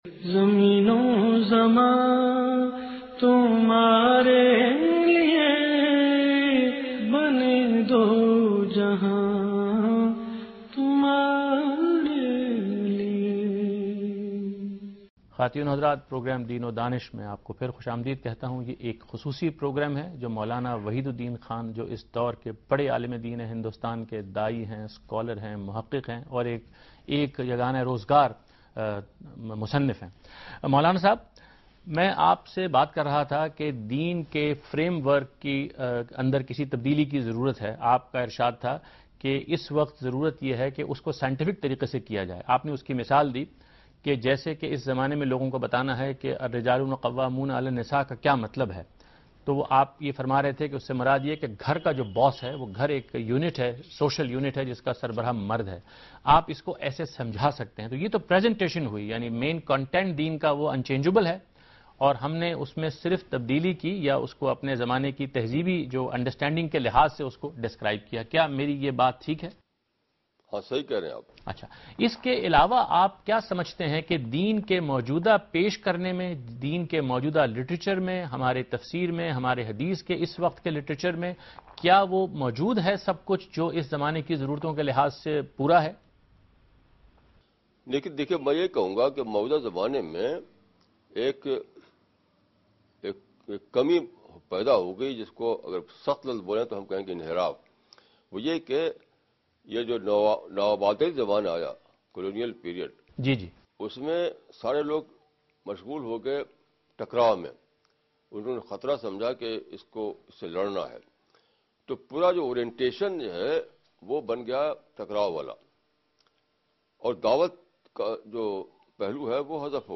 Special Episode of program "Deen o Daanish" with Mowlana Wahidud Din from India.
دنیا ٹی وی کےاس پروگرام میں مولانہ وحید الدین خان "نبی اکرم کی زندگی اور ہماری ذمہ داریاں" کے متعلق ایک سوال کا جواب دے رہے ہیں